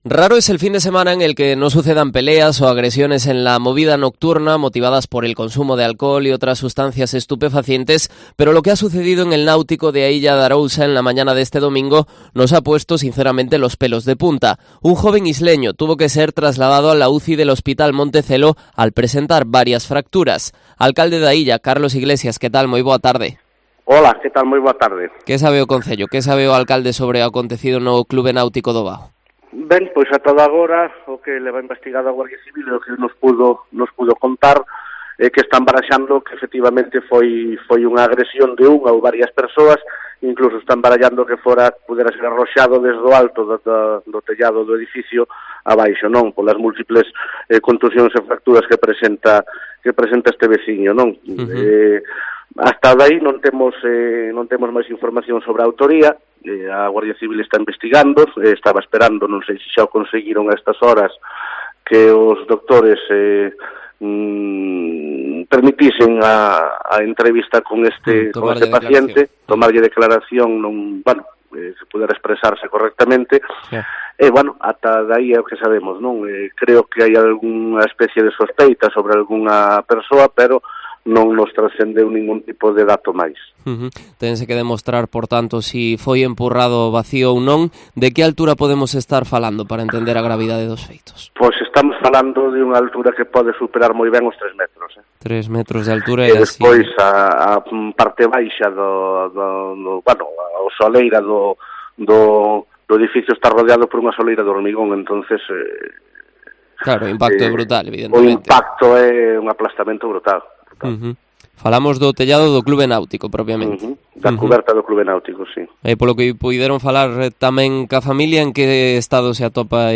Entrevista del alcalde de A Illa de Arousa, Carlos Iglesias, en Cope Pontevedra